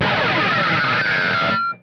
pickslide1
distortion guitar noise pick scratch slide sound effect free sound royalty free Music